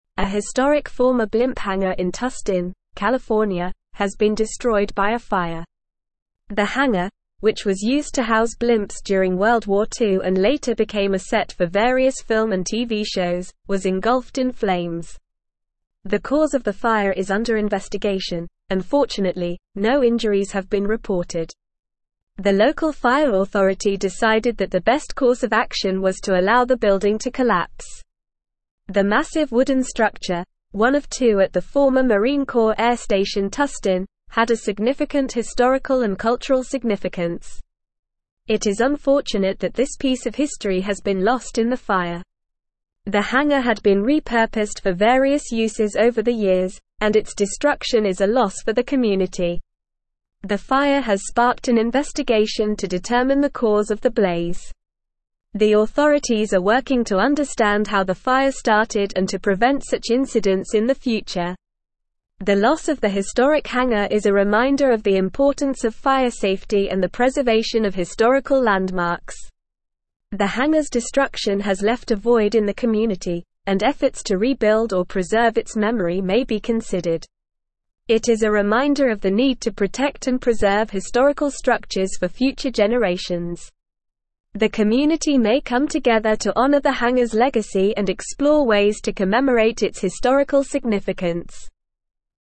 Normal
English-Newsroom-Advanced-NORMAL-Reading-Historic-World-War-Two-Era-Blimp-Hangar-Engulfed-in-Flames.mp3